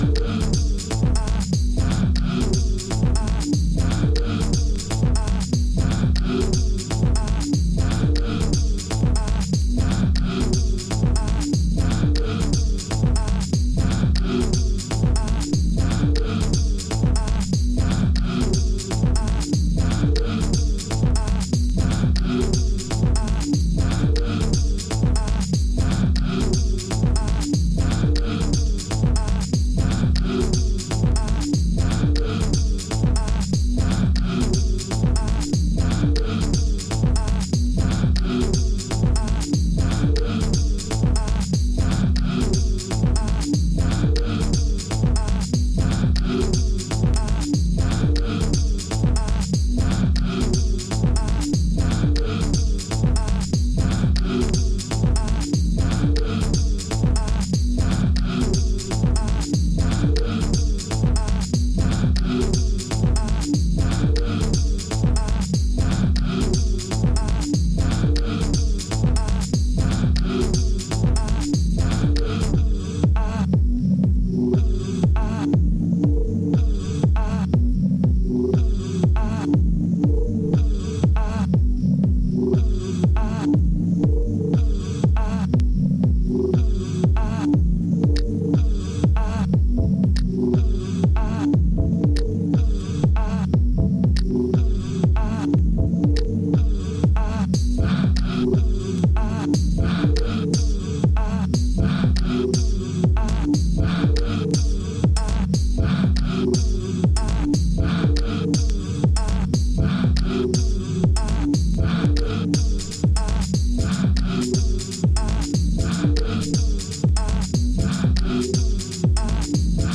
nice airy and live sounding broken beats
hi-tek soul
heavyweight beatdown
Deep house